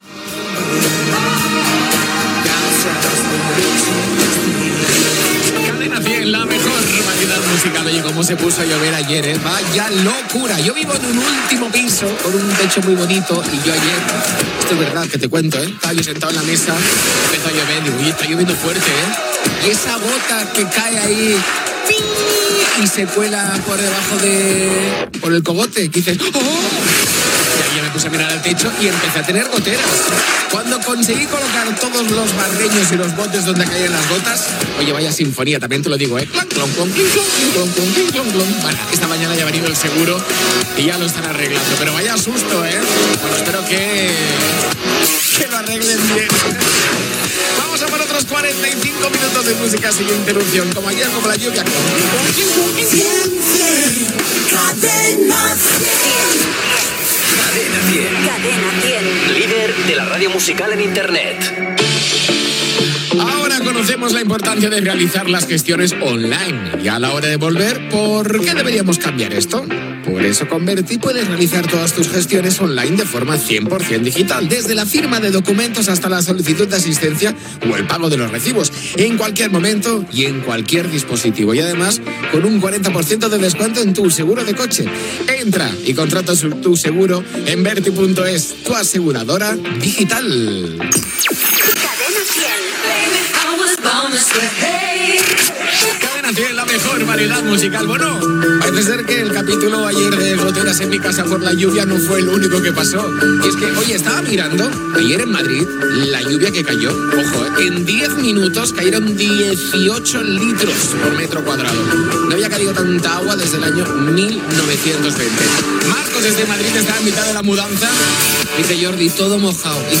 Identificació de la ràdio, comentari sobre la pluja forta del dia anterior, indicatiu, publicitat, indicatiu, dades de la pluja recollida a Madrid, comentaris de l'audiència, tema musical
Musical